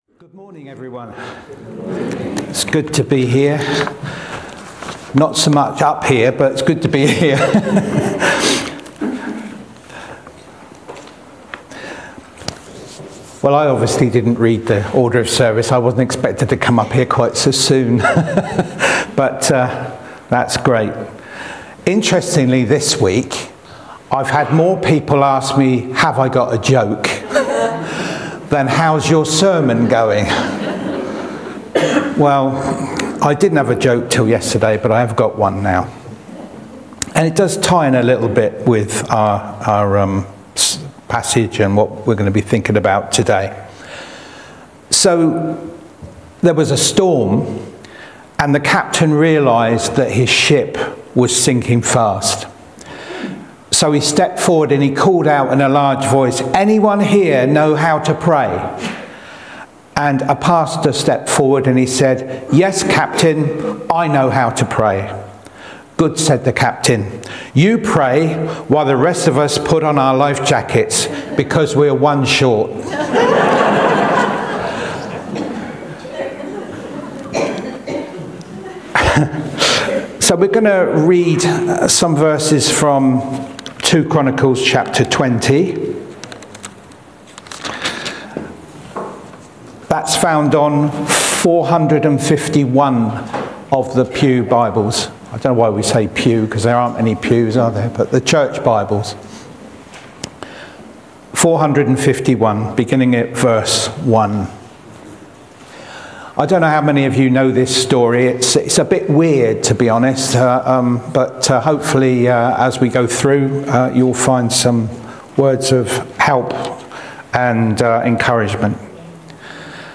Talks and Sermons - Thornhill Baptist Church
12th-February-Prayer-Changes-Us-Sermon-Audio.mp3